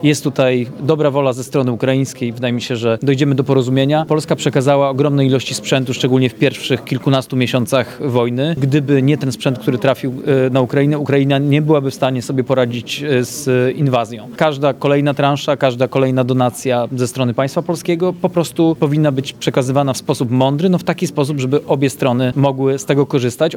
Trwają rozmowy ze stroną ukraińską na temat przekazania przez Polskę samolotów MiG-29 w zamian za ich systemy dronowe. O postępach w negocjacjach mówił w Lublinie wiceminister obrony narodowej Cezary Tomczyk.